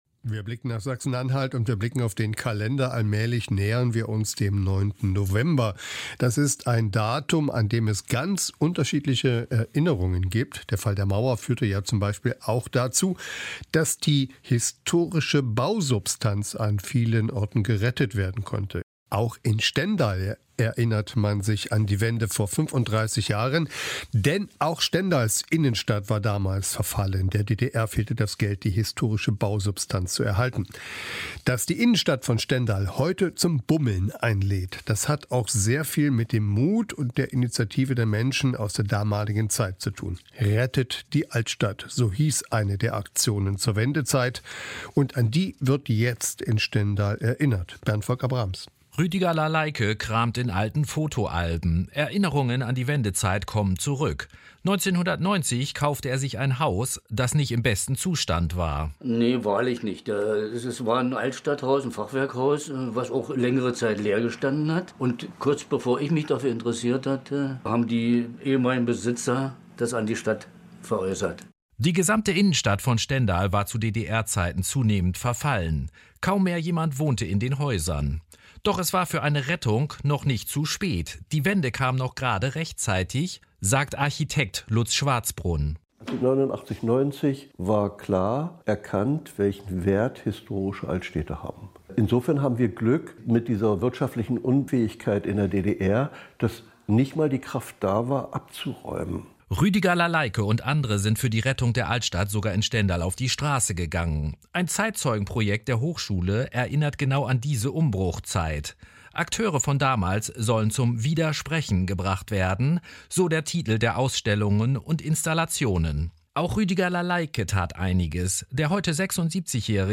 MDR, 29. Oktober 2024 [Radiobeitrag]